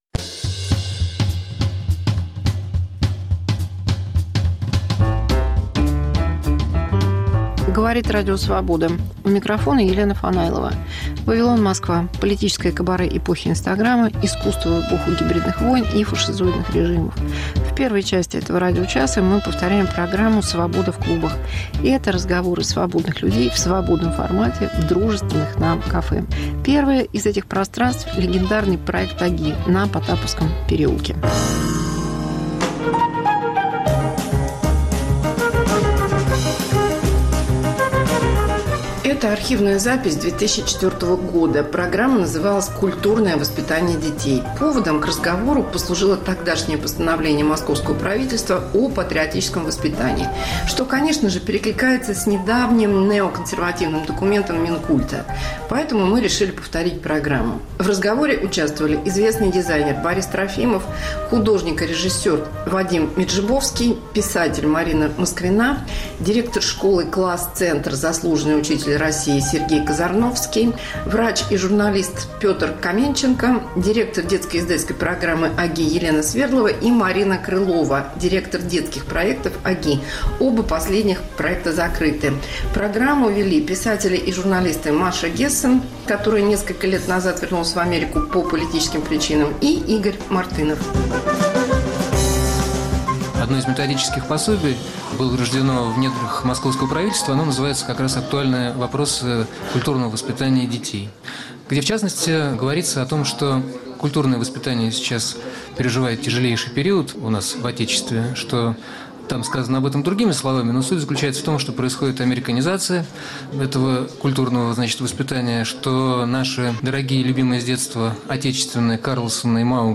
Мегаполис Москва как Радио Вавилон: современный звук, неожиданные сюжеты, разные голоса. 1. Из архива 2004: терроризм в России, правомерность "исламского террора" в стране. 2. Эпидемия COVID-19 c политической и философской точки зрения. Интервью